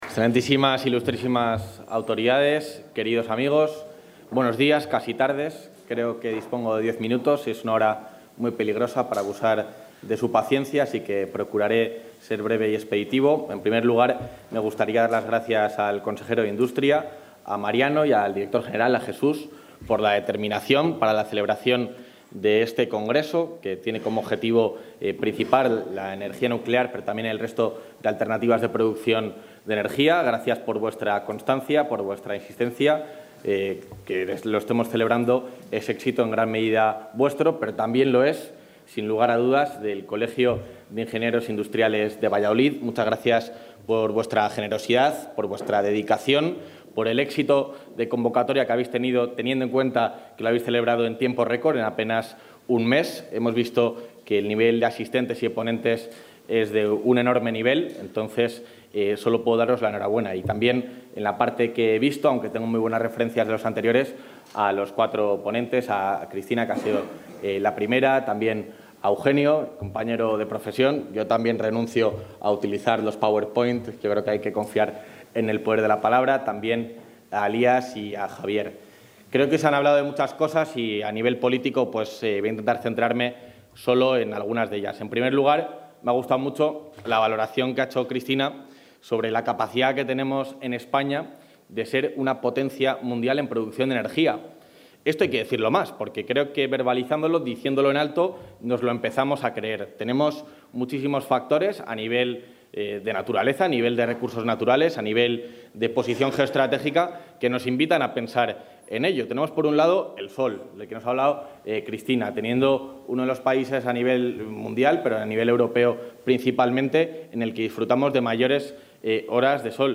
Intervención del vicepresidente.
Clausura del I Congreso Internacional Energía Nuclear en la Industria